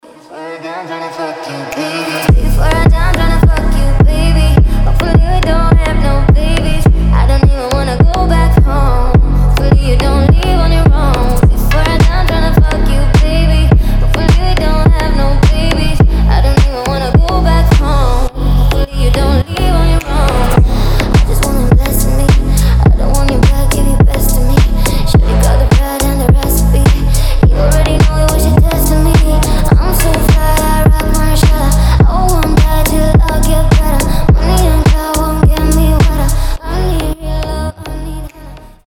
• Качество: 320, Stereo
deep house
чувственные
красивый женский голос